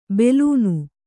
♪ belūnu